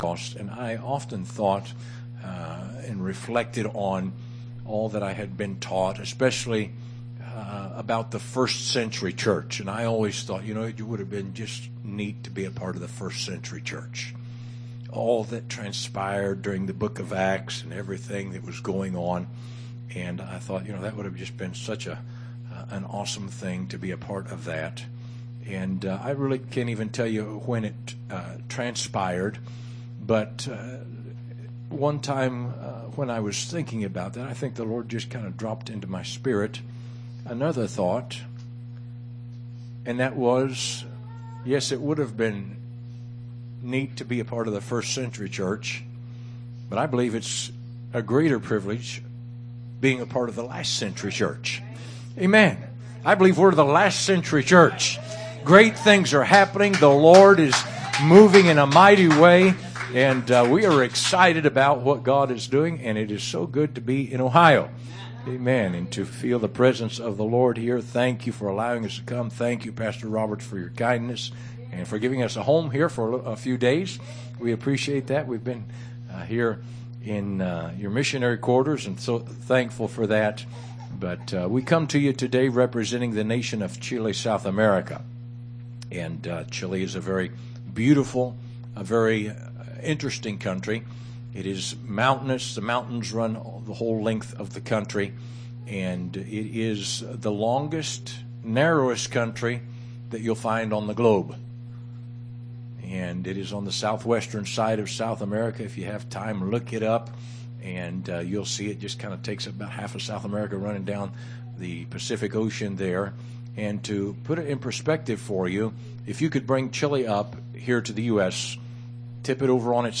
Missionary from Chile